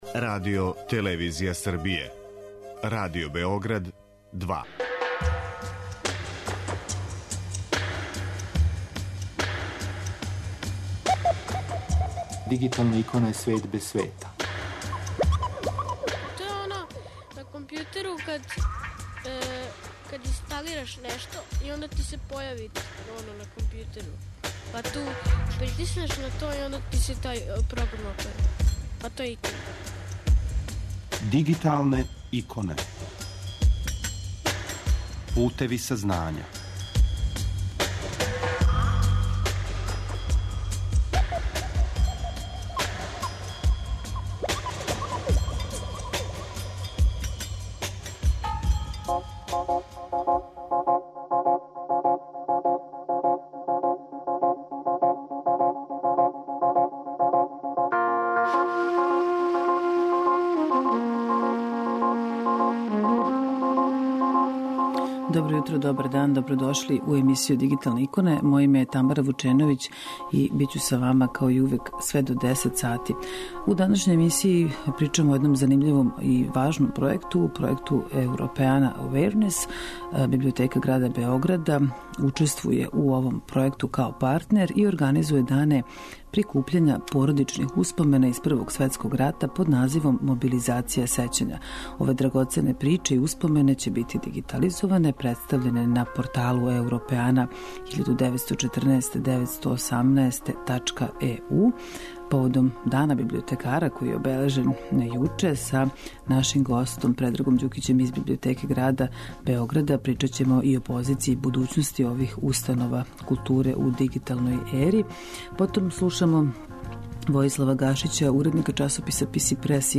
биће са нама уживо на таласима Радио Београда 2